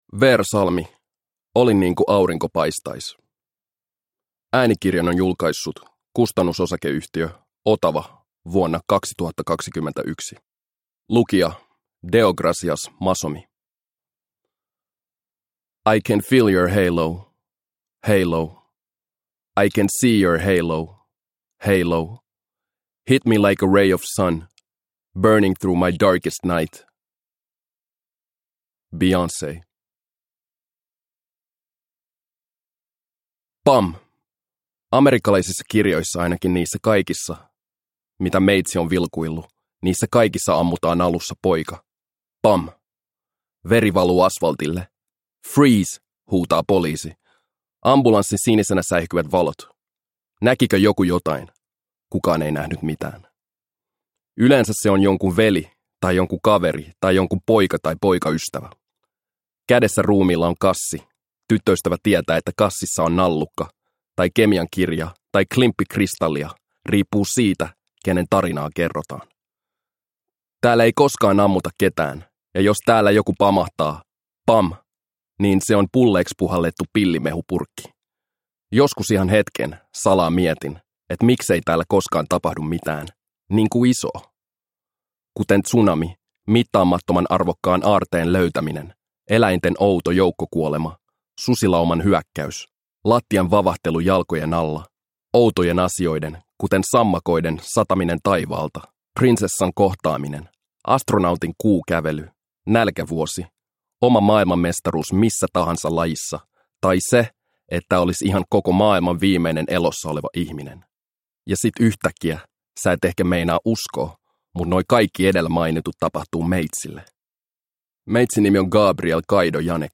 Olin niinku aurinko paistais – Ljudbok – Laddas ner